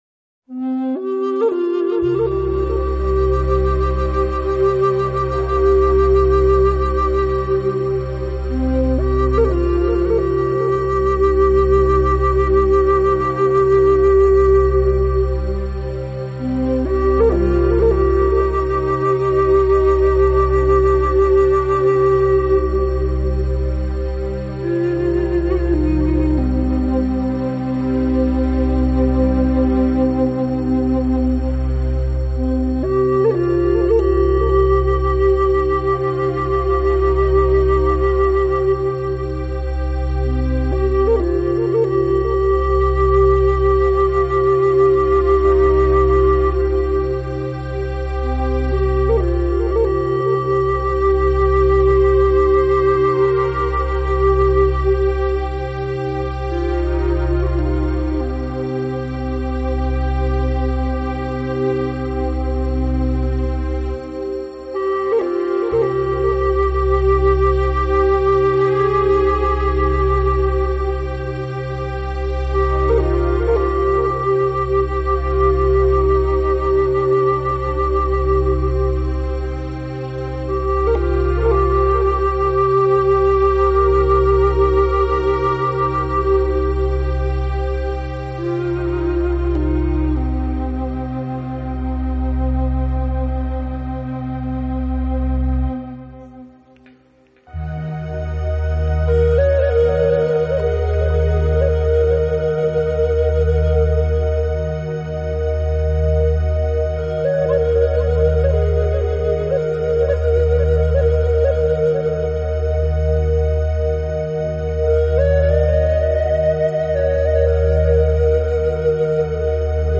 专辑风格：New Age / Meditative / World